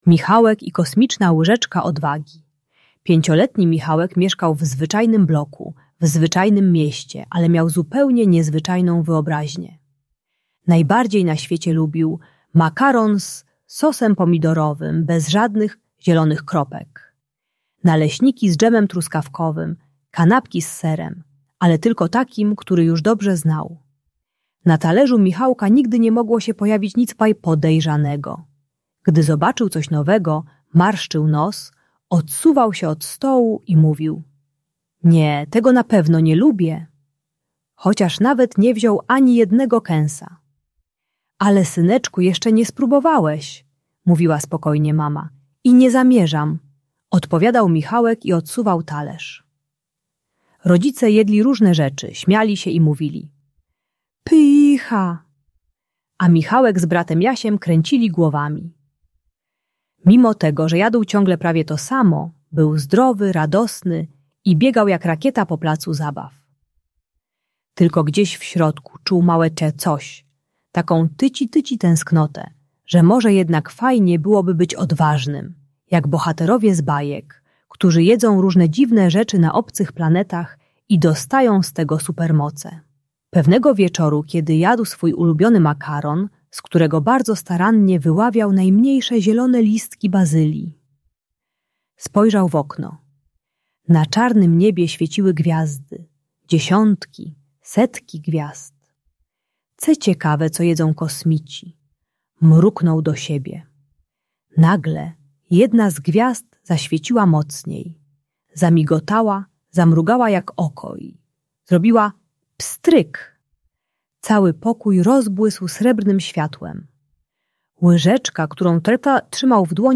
Michałek i Kosmiczna Łyżeczka Odwagi - Problemy z jedzeniem | Audiobajka